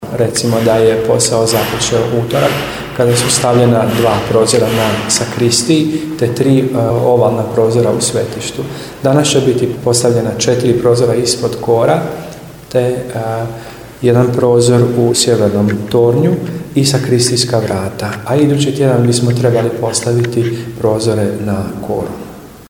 U akustičnom prostoru i bušilica nekako melodično zvuči, tako da je upravo taj radni prostor unutar crkve bio teren s kojeg prenosimo riječi, zvukove, radnu energiju…